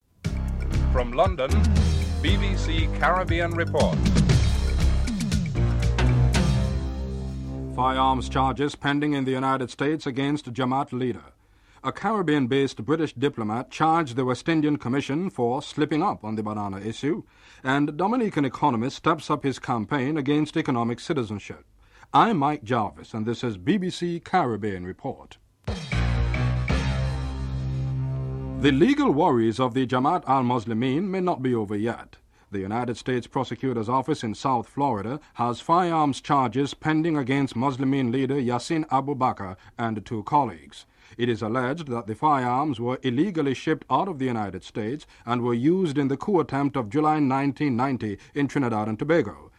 1. Headlines (00:00-00:27)
Spokesman for the prosecutor’s office Dan Gelber is interviewed about the charges (00:28-01:59)